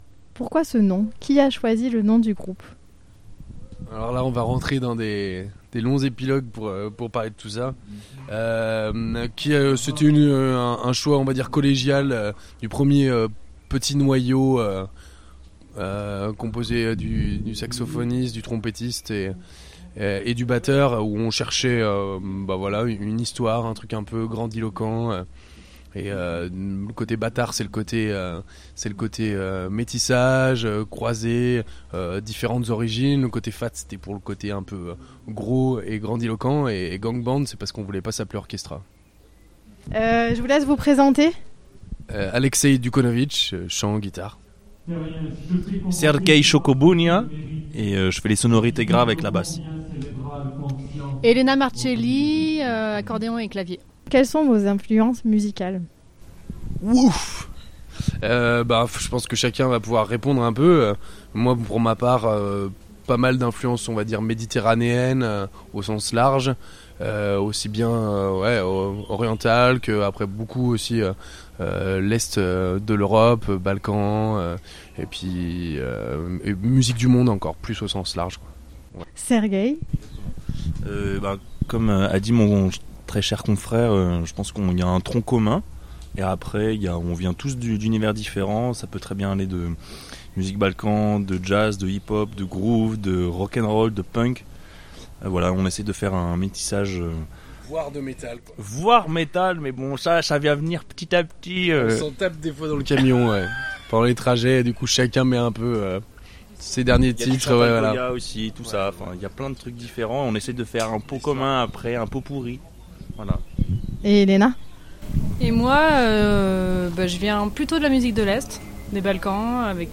3 membres du groupe vous en disent plus sur leur univers.